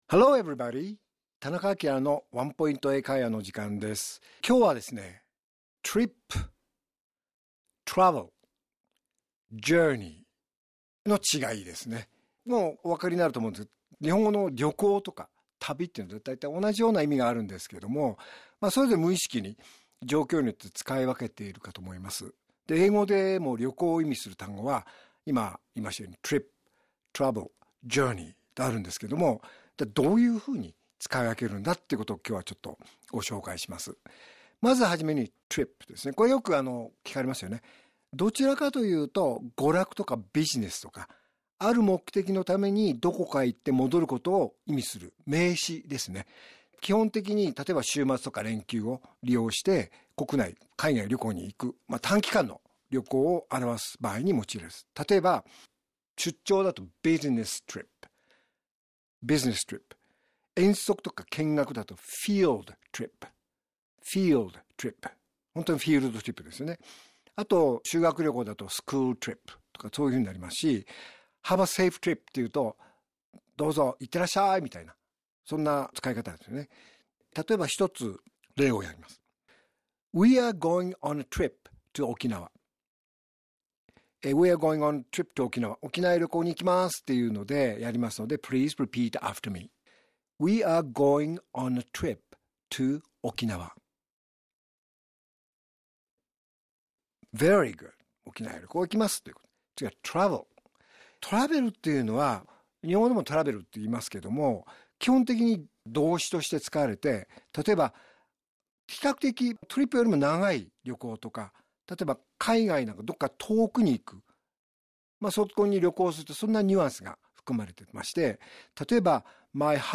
R6.3 AKILA市長のワンポイント英会話